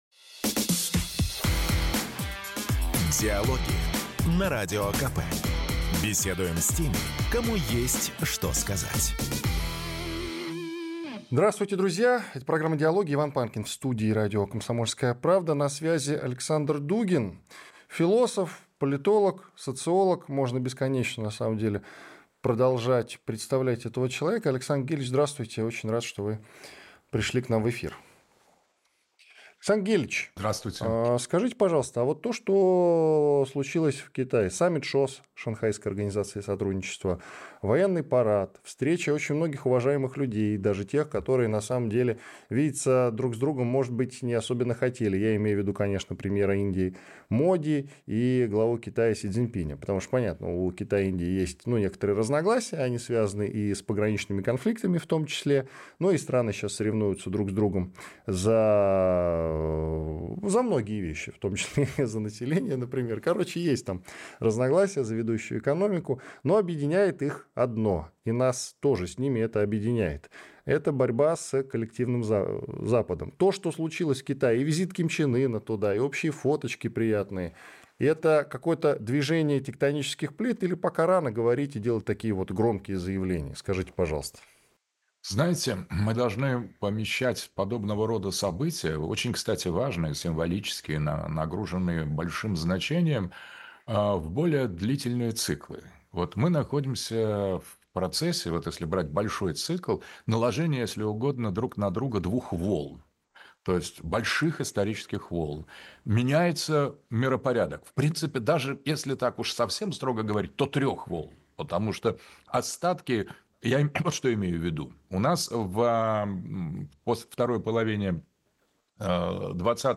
В программе участвует Александр ДУГИН, философ, политолог Ведущий